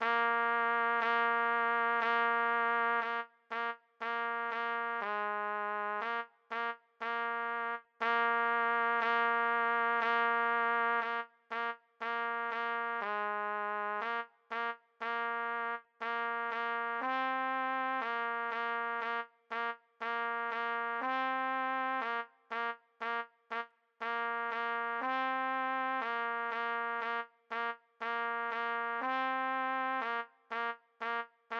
D=Counter-melody/Harmony/Bass Part-for intermediate to experienced players